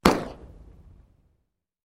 Еще один вариант с выстрелом